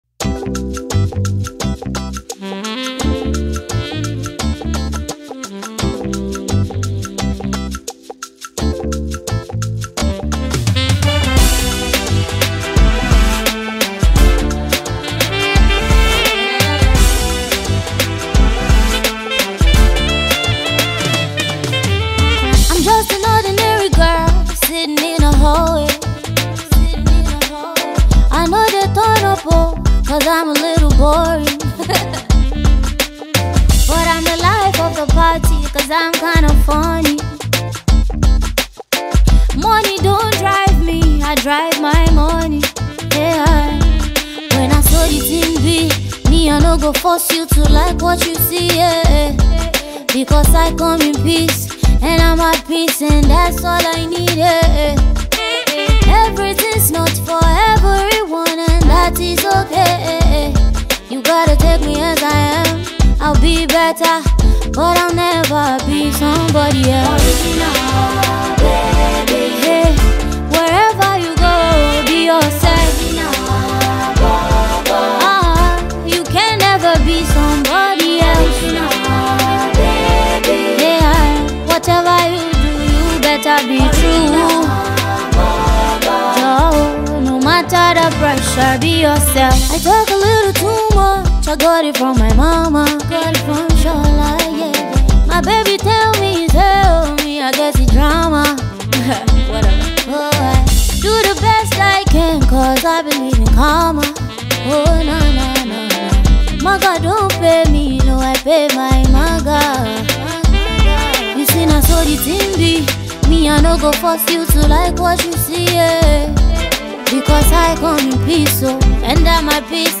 beautiful vocals